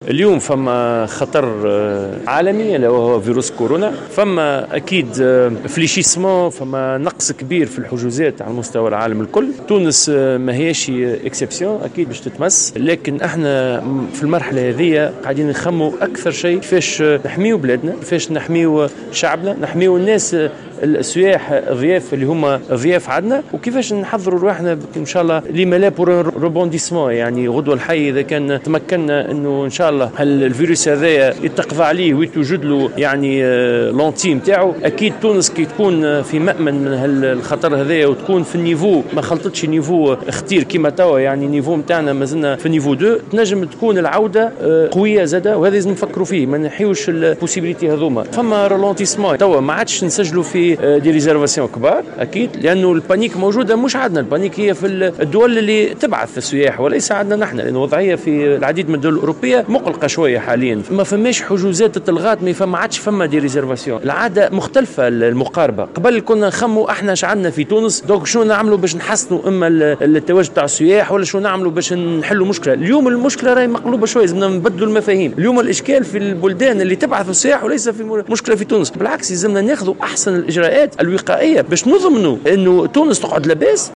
أكد وزير السياحة والصناعات التقليدية، محمد علي التومي، في تصريح للجوهرة أف أم، أن النزل التونسية سجلت تباطئا في حجم الحجوزات، تأثراً بانتشار فيروس كورونا المستجد، خصوصا في الأسواق السياحية التقليدية لتونس.
وشدد التومي، على هامش الجلسة العامة العادية الإنتخابية للجامعة التونسية للنزل، اليوم الخميس، على إمكانية استغلال تونس للظرف الحالي، في حال بقي انتشار الفيروس محدودا في البلاد، من أجل تحقيق عودة سياحية قوية.